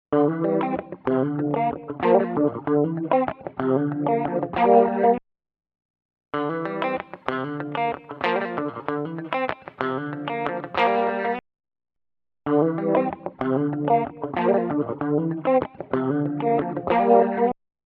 渦巻くクラシック・ロータリーサウンド
Rotary Mod | Electric Guitar | Preset: JazzyRotary
Rotary-Eventide-Electric-Guitar-JazzyRotary.mp3